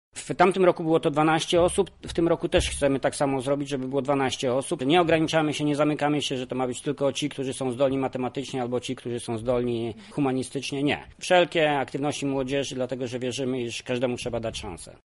O tym, ile osób obejmie druga edycja stypendium, mówi sam fundator Jacek Bury: